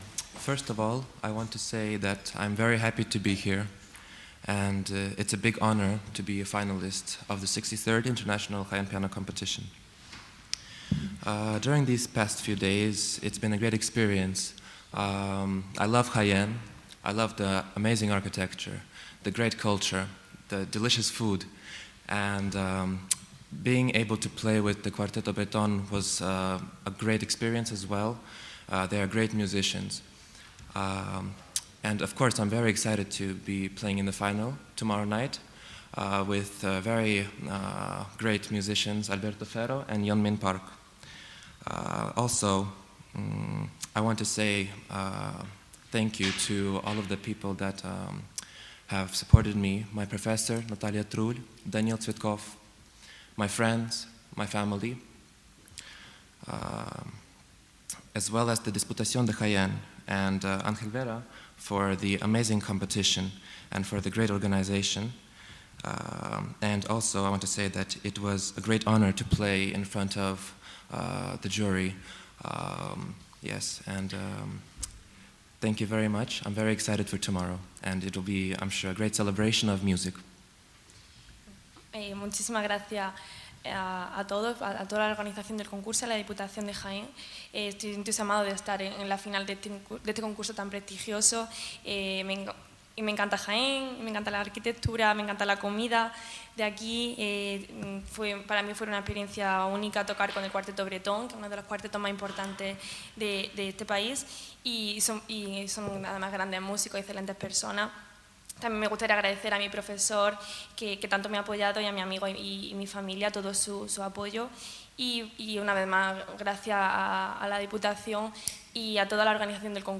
Declaraciones en audio de los finalistas